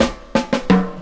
3 Step Roll 175bpm.wav